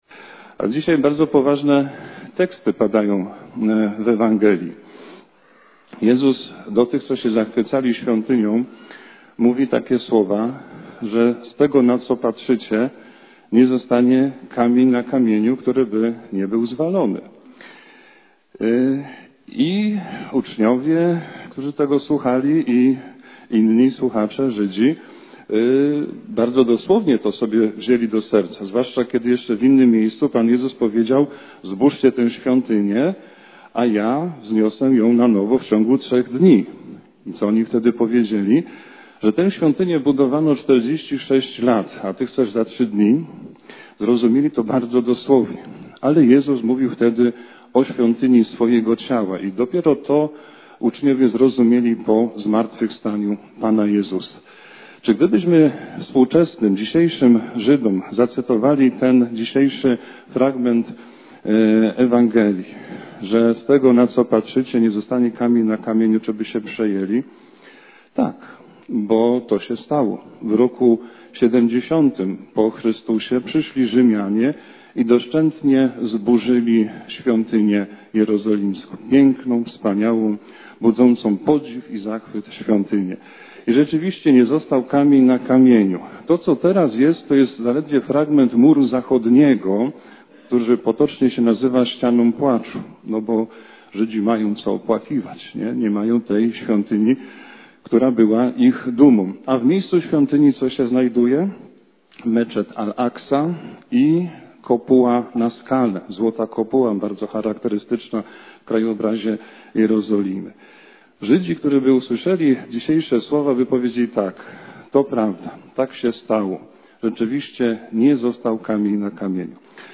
Kazanie z 33. Niedzieli Zwykłej – 16.11.2025